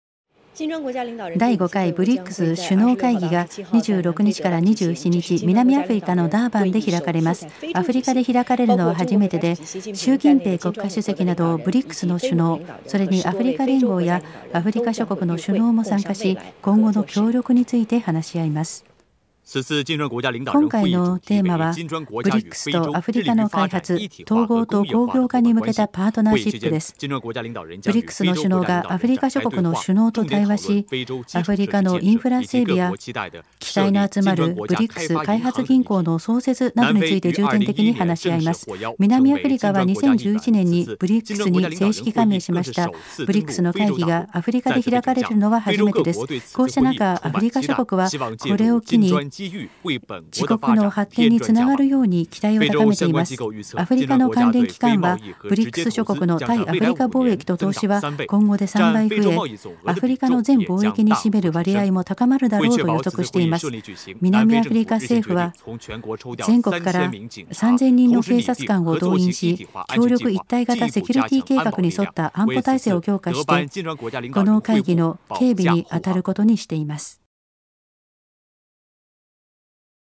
第一部、第二部は多彩な話者による語り下ろしのスピーチを収め、第三部はCCTV大富の放送から、15分野51本のニュースをピックアップ。音声は、中国語原音、同時通訳入り音声（第二部、第三部）、学習用にスピードを落として読み直したゆっくりバージョン（第三部）を合計8時間以上収録。